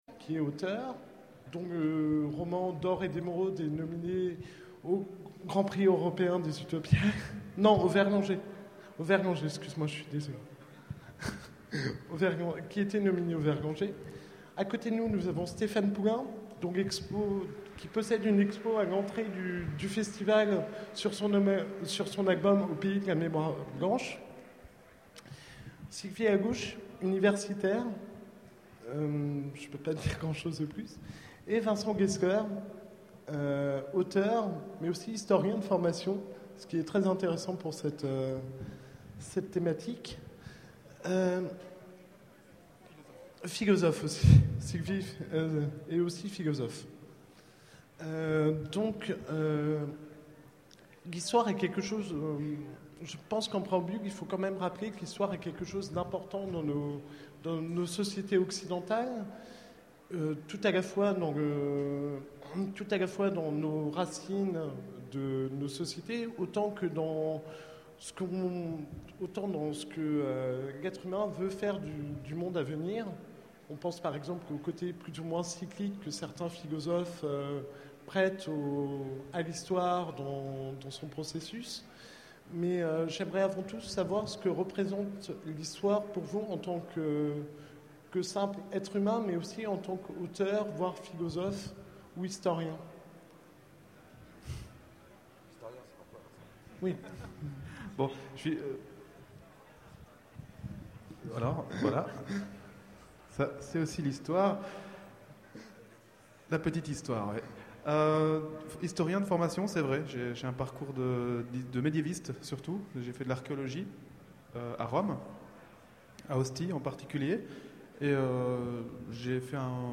Utopiales 2011 : Conférence Qu'apprenons-nous de l'histoire ?